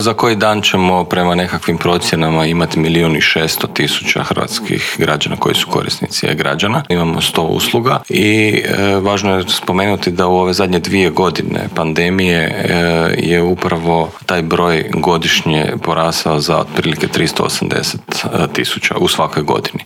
ZAGREB - U Intervjuu tjedna Media servisa gostovao je državni tajnik u Središnjem državnom uredu za razvoj digitalnog društva Bernard Gršić koji nam je otkrio kako je proteklo prvo online samopopisivanje građana, koliko ljudi koristi sustav e-Građani, što sve taj sustav nudi, što planira ponuditi kao i koje su njegove prednosti.